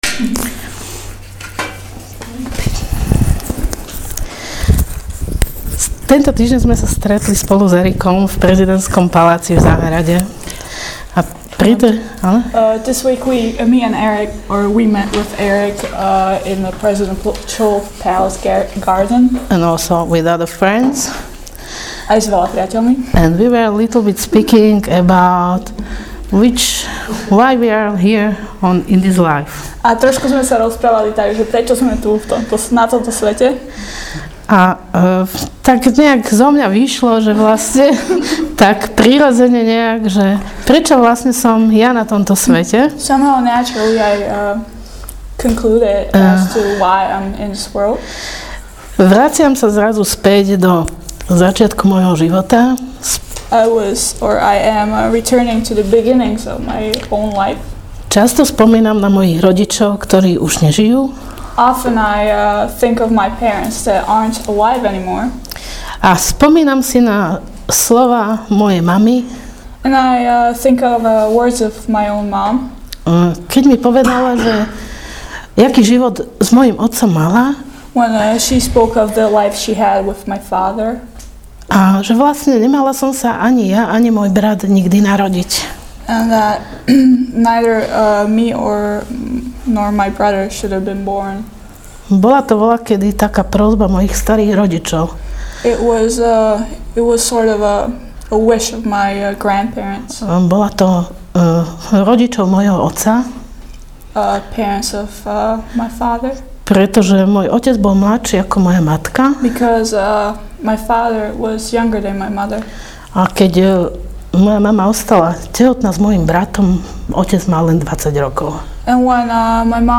Also a great testimony